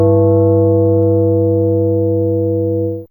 ChimesG1G1.wav